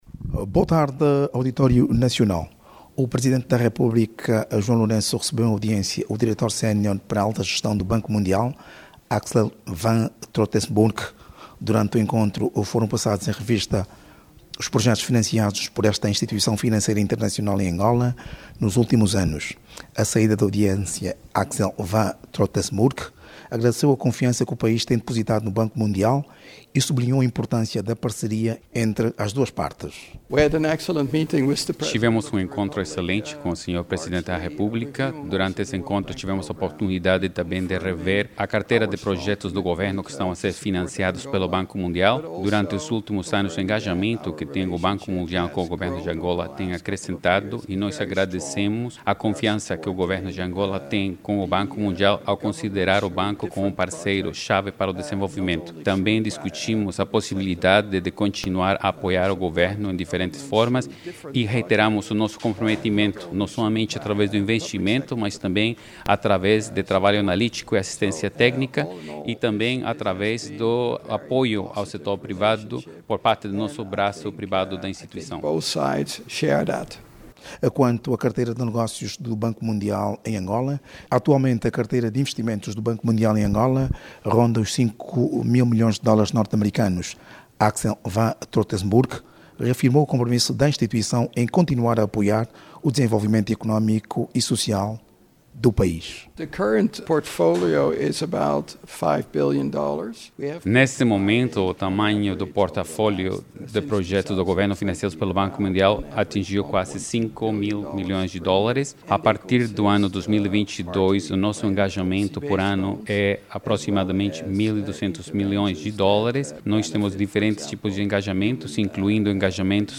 Jornalista